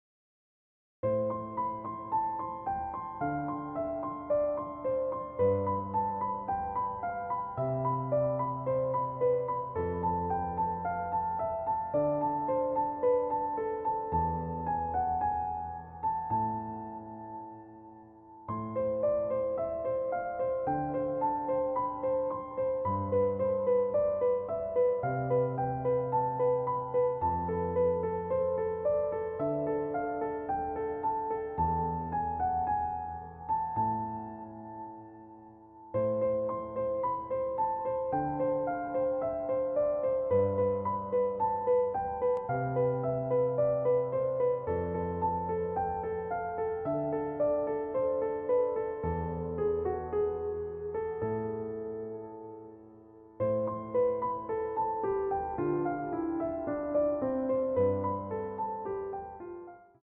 EASY Piano Tutorial